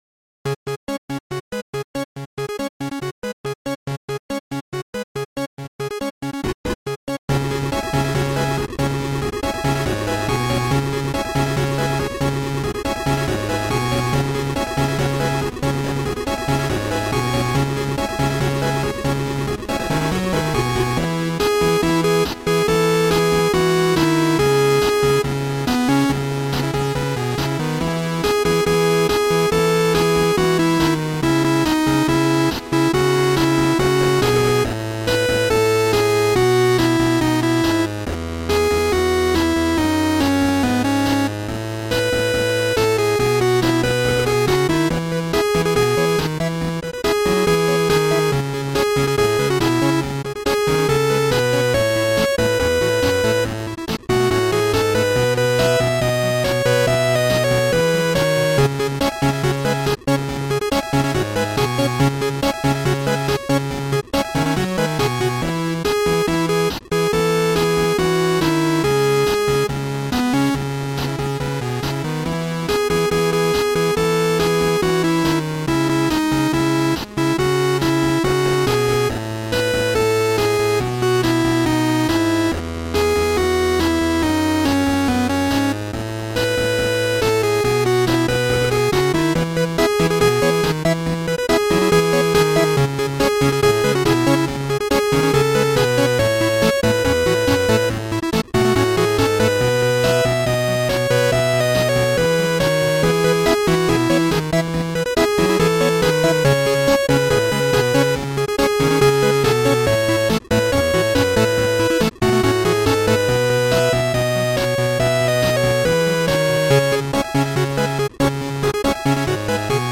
8-bit chiptune